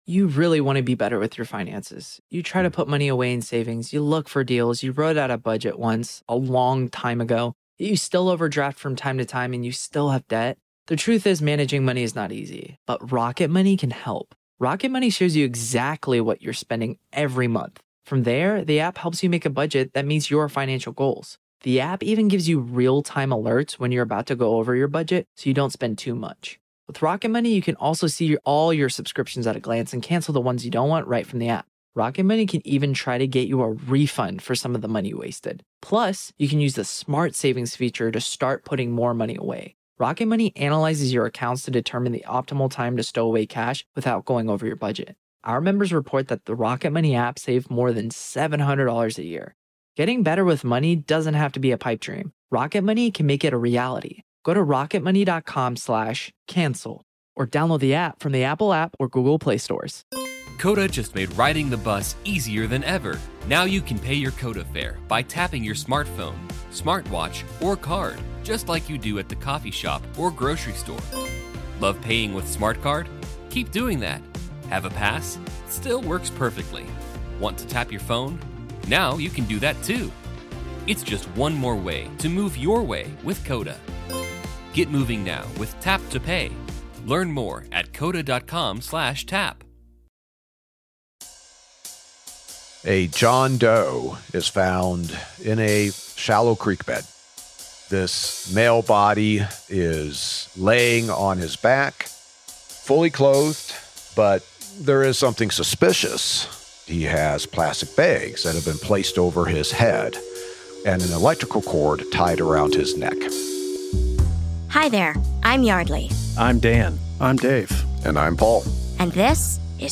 Host Paul Holes takes us through a case he worked on in the late 1990s, where a claim of self-defense was ultimately turned on its head after a thorough and objective look at the nearly invisible trace evidence left behind after the body of a gay man was found dumped in a ditch.